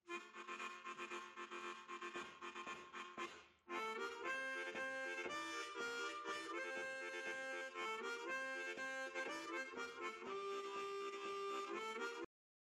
I’m stumped - any idea how it’s possible to simultaneously play a note (like in the middle and end or the audio file) while overlaying the “tududu tududu” sounds - seems like magic.
Sounds like tongue blocking and tongue slapping?